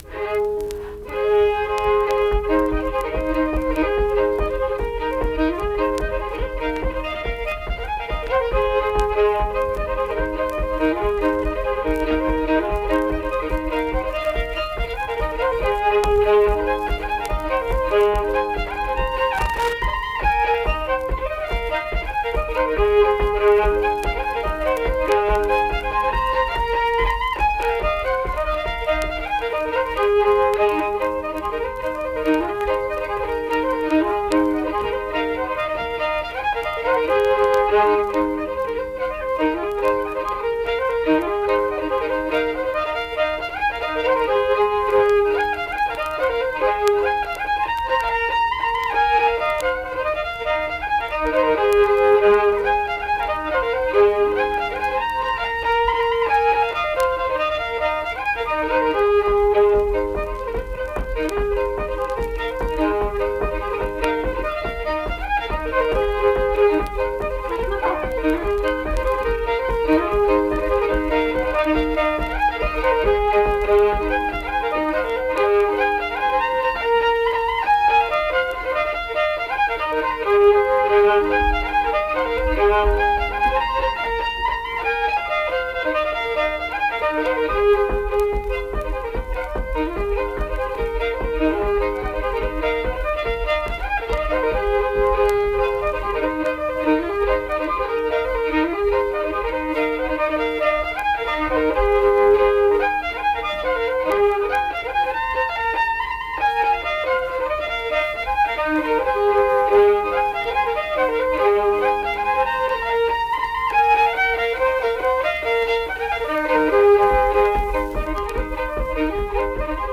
Hop Light Ladies - West Virginia Folk Music | WVU Libraries
Unaccompanied fiddle music
Instrumental Music
Fiddle